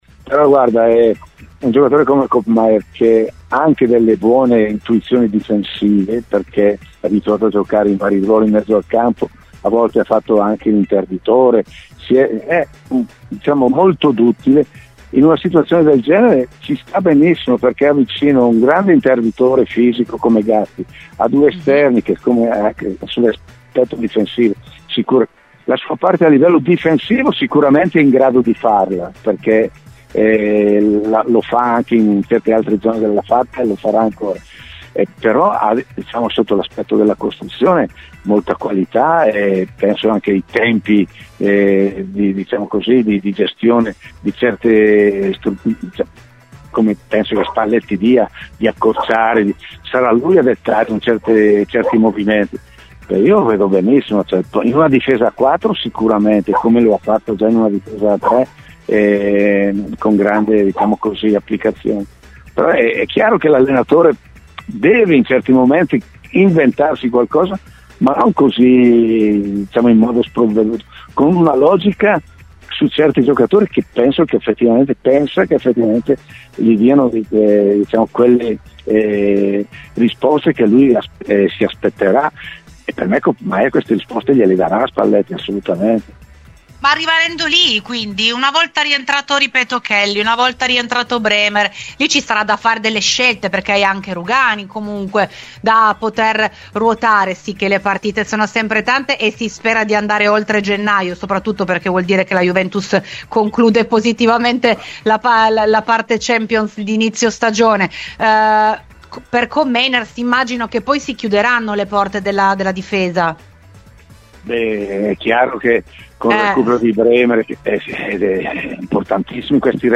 L'ex allenatore ha parlato durante Rbn Cafe.